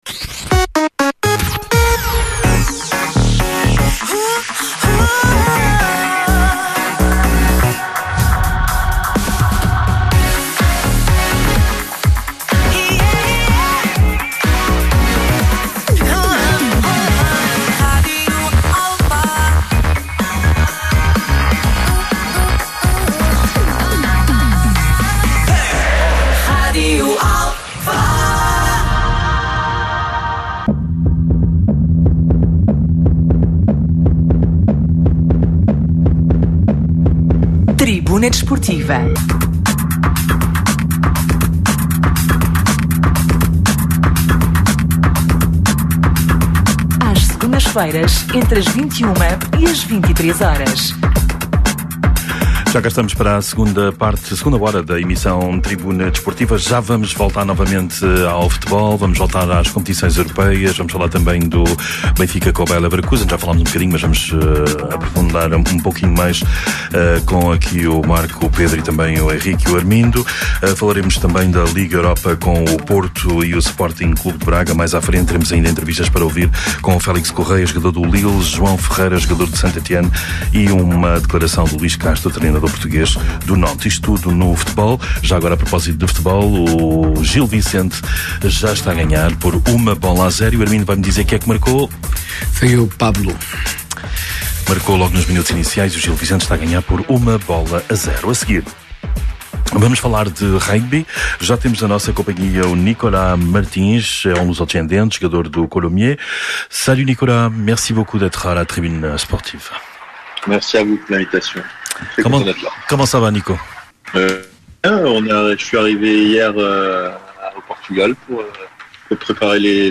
Atualidade Desportiva, Entrevistas, Comentários, Crónicas e Reportagens.
Tribuna Desportiva é um programa desportivo da Rádio Alfa às Segundas-feiras, entre as 21h e as 23h.